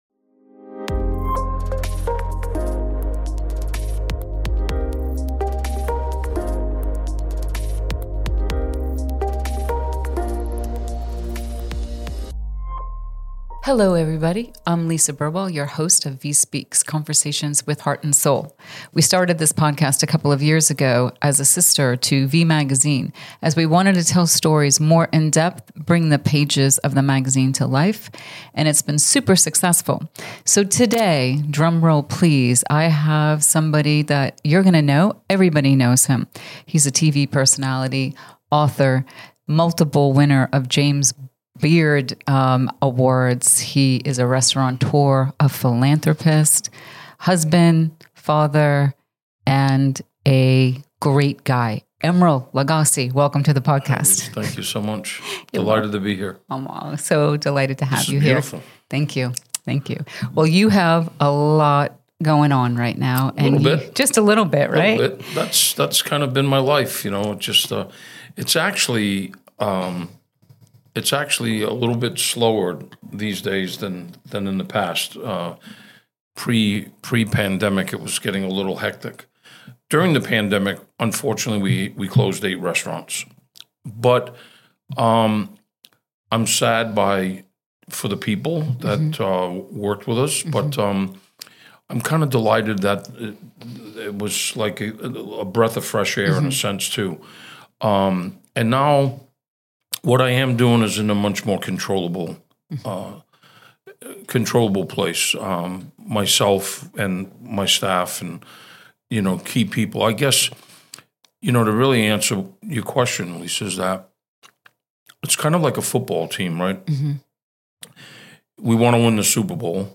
44. "A Name Everyone Knows" – A Conversation with Chef Emeril Lagasse